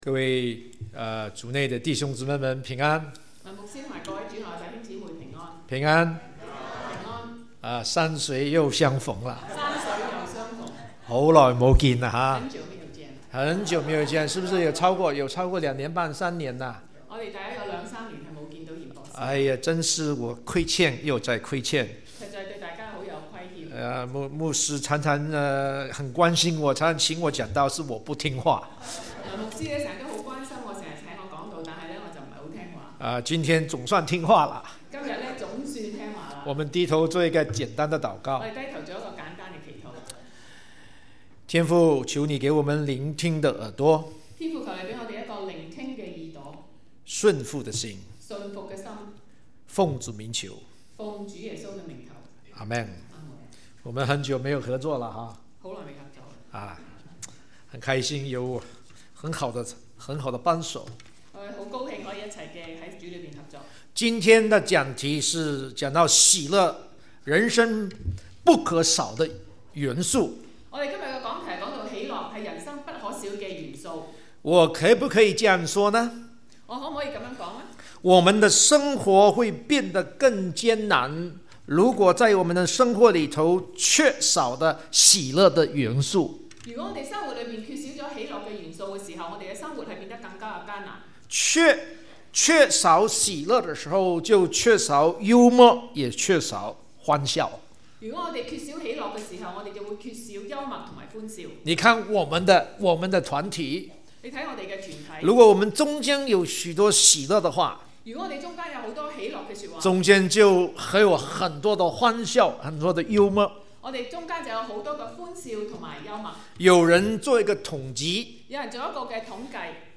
國語講道 - 粵語傳譯 Bible Text